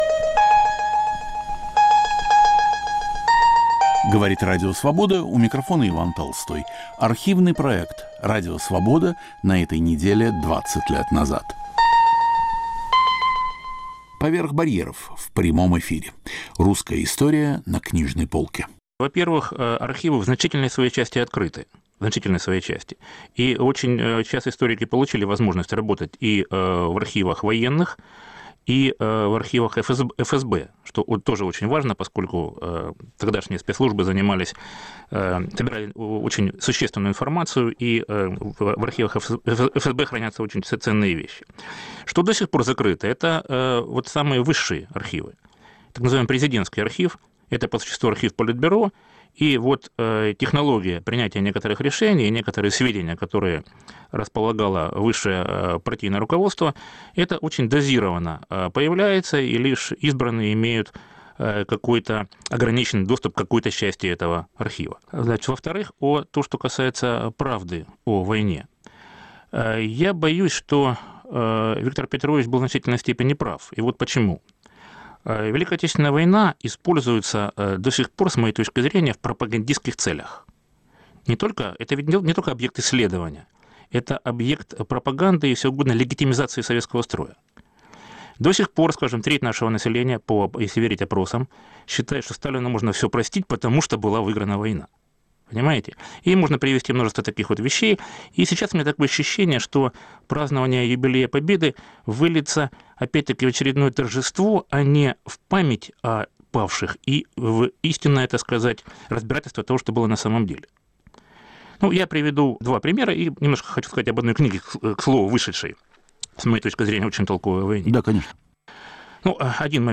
"Поверх барьеров" в прямом эфире. Русская история на книжной полке